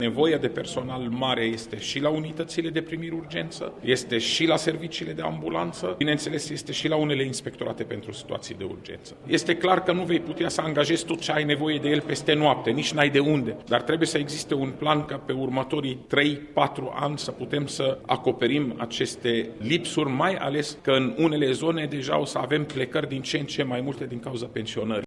El a participat la primul bilanţ anual al unei structuri IGSU, care a avut loc ieri la Reşiţa. Demnitarul şi-a arătat preocuparea pentru lipsa de personal din structurile de salvare: